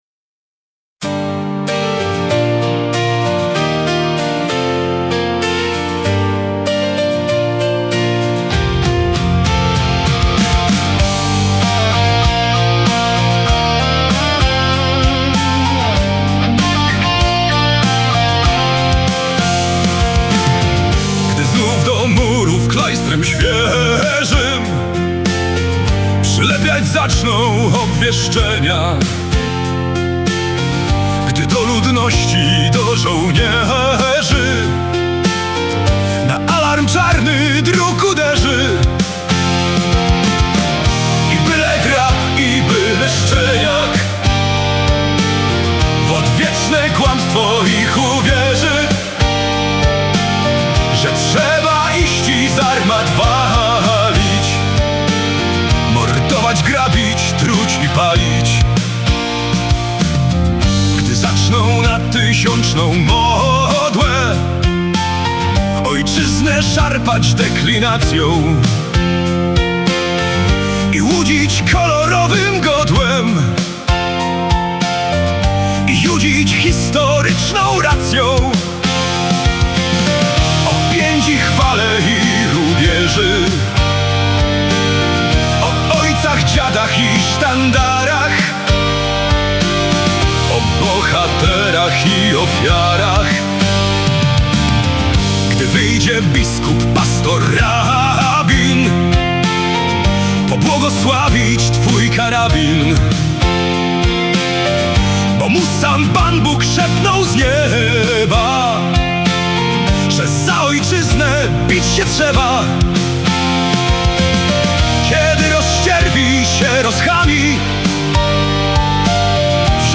produkcja AI.